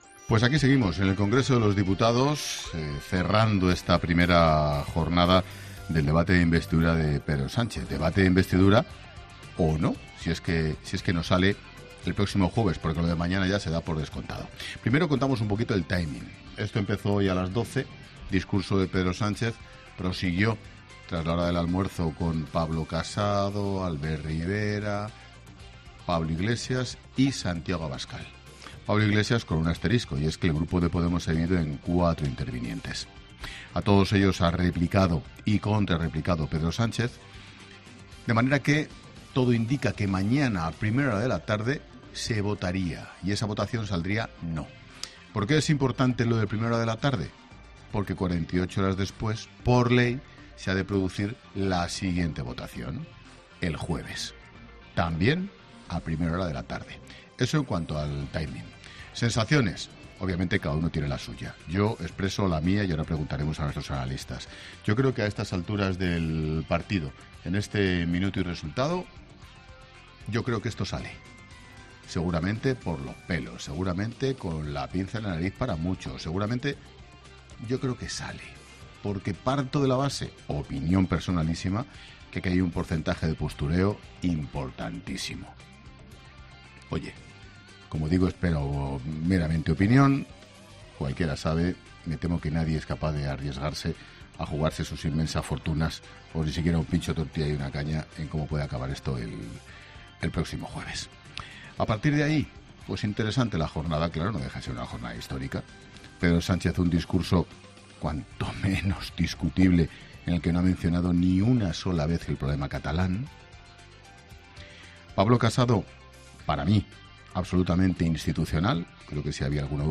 Boletín de noticias de COPE del 22 de julio de 2019 a las 21.00 horas